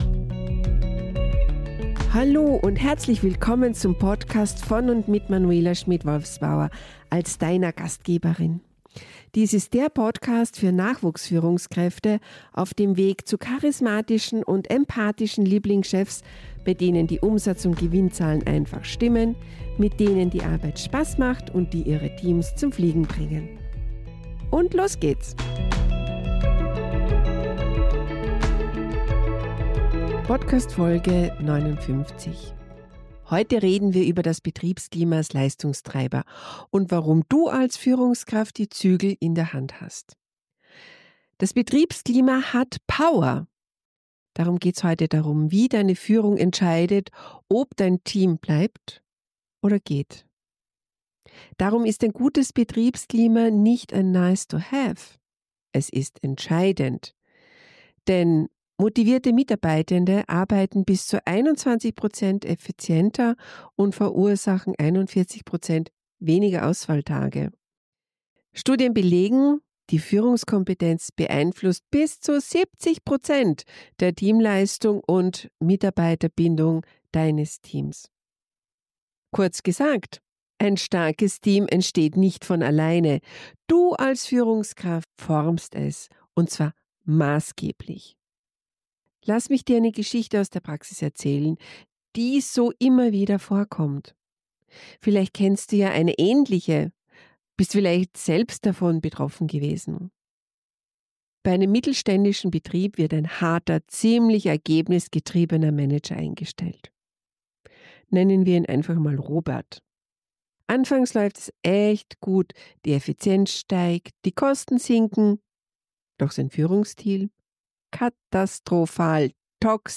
In dieser kraftvollen Solo-Folge